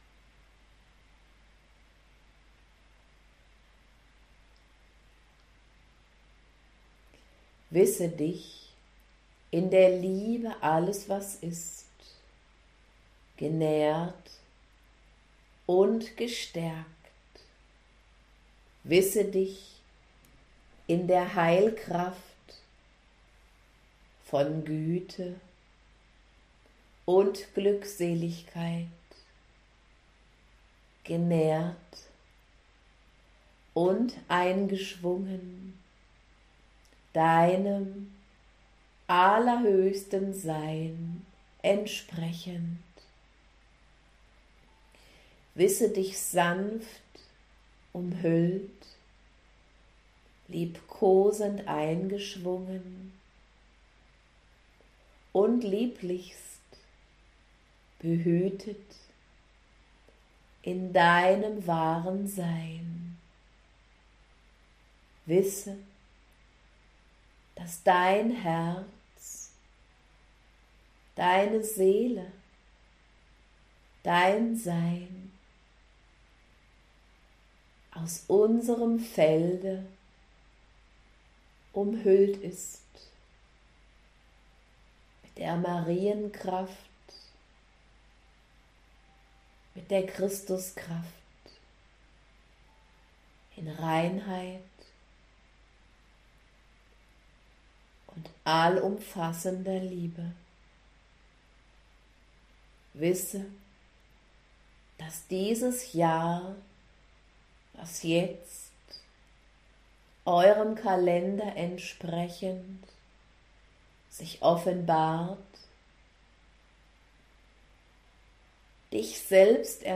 ♡"Marienjahr der Liebe" Auszug Channeling